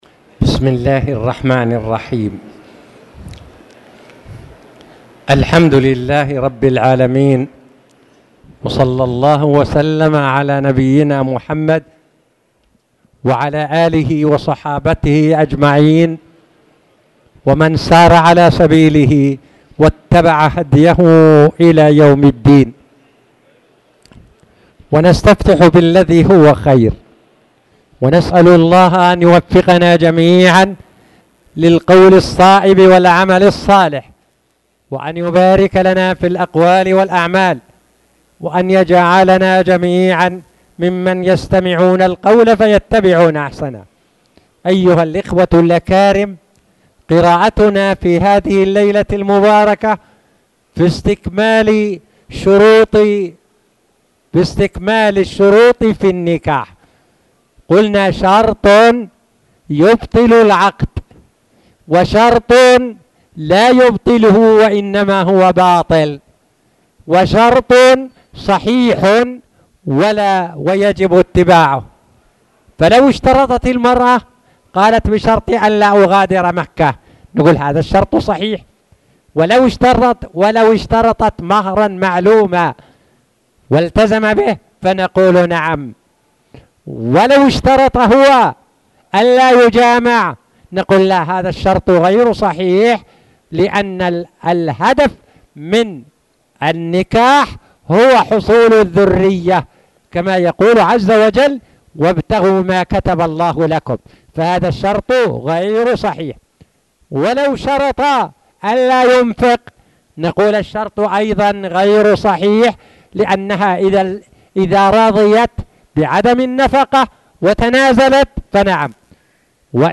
تاريخ النشر ٢٦ ربيع الأول ١٤٣٨ هـ المكان: المسجد الحرام الشيخ